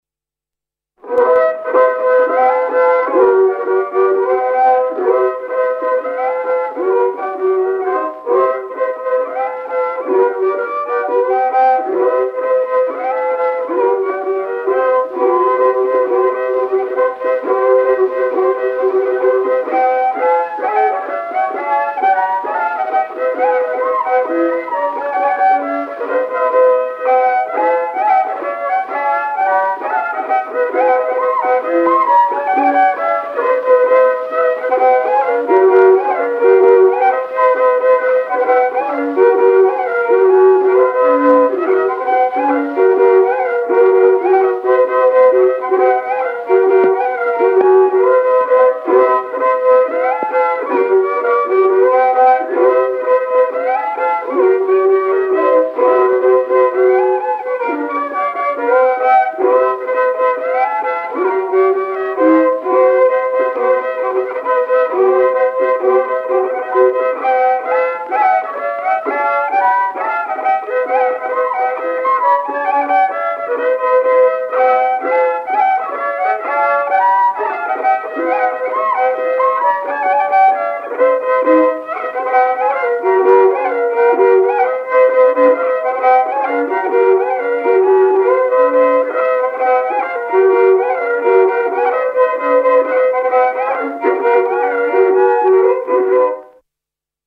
viiul
22 Polka.mp3